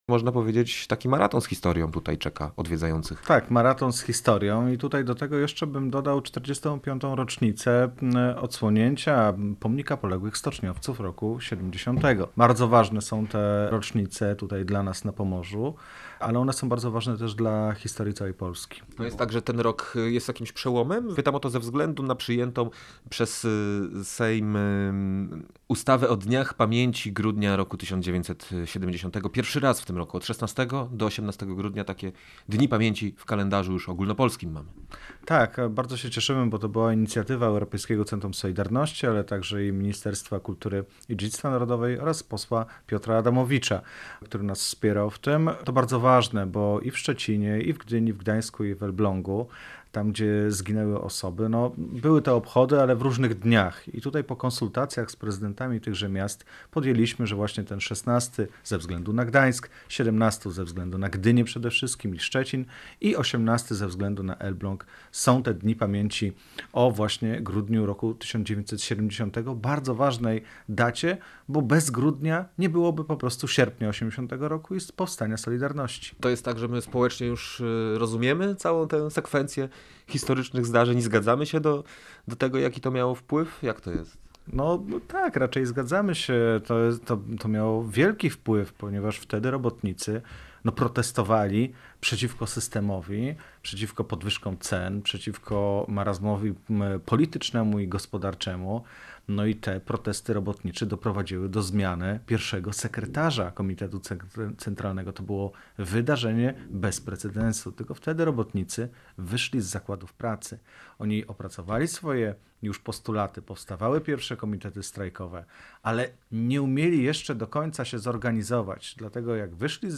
Posłuchaj całej rozmowy o znaczeniu Dni Pamięci Grudnia 1970, a także o lokalnych wydarzeniach z nimi związanych: